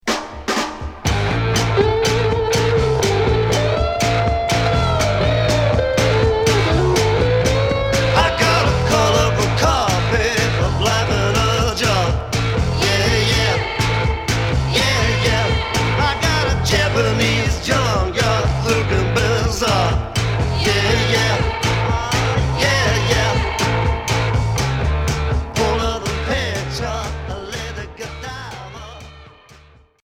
Heavy rock pop psyché Premier 45t retour à l'accueil